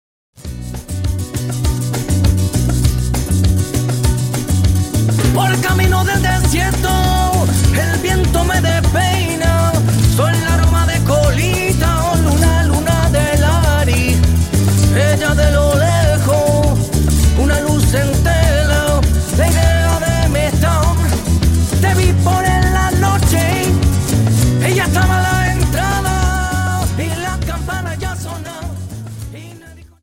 Dance: Samba Song